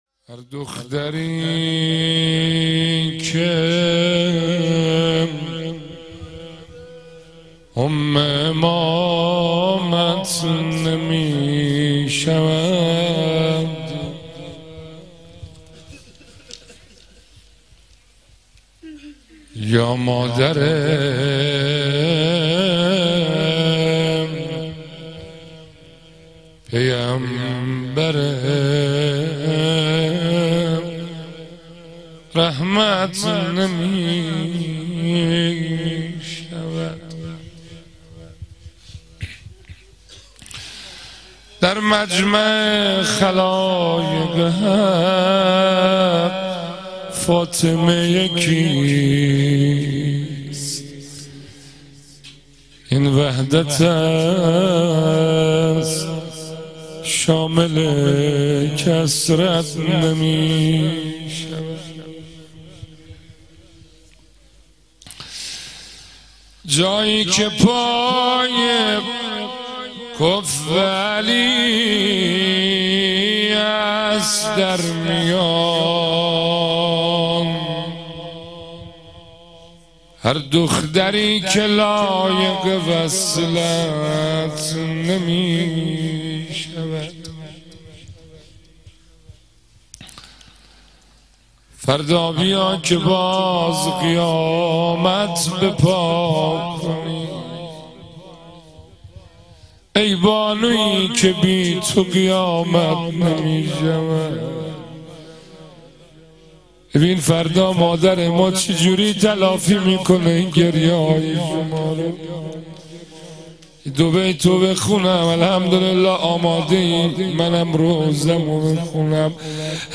روضه | هر دختری که اُم امامت نمی‌شود